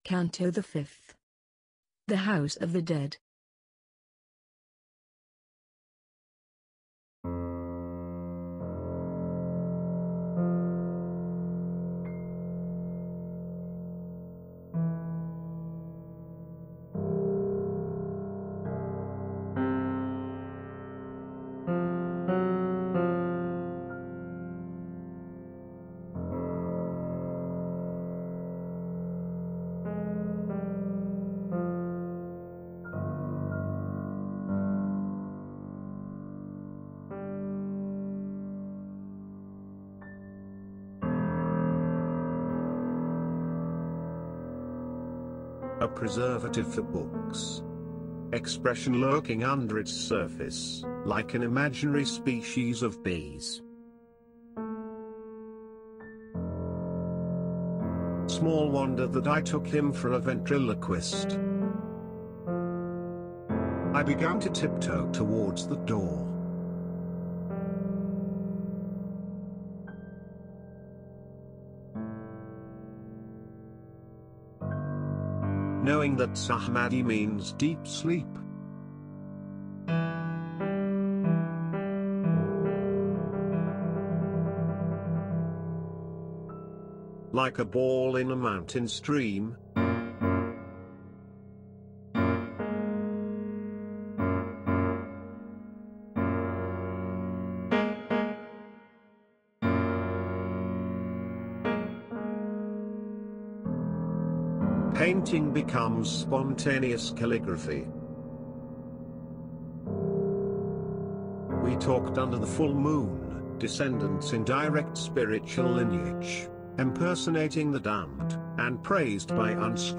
The voices reciting the texts are synthesized.